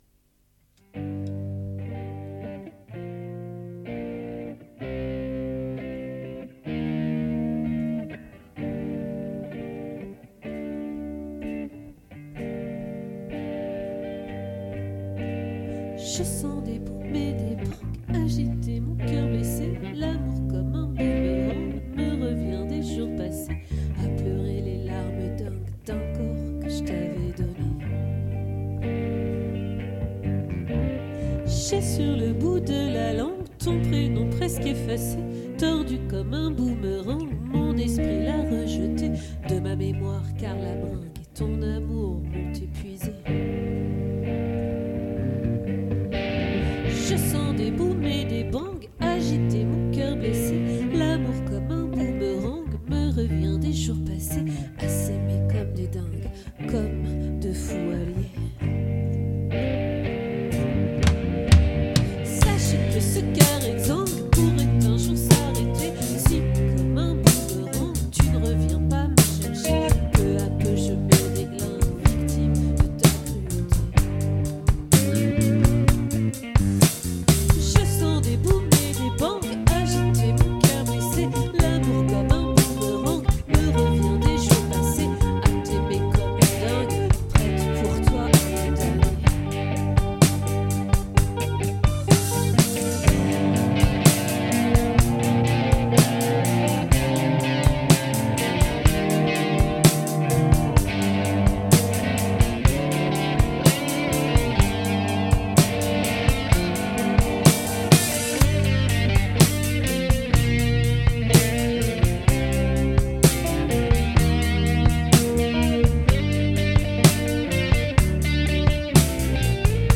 🏠 Accueil Repetitions Records_2024_02_20_OLVRE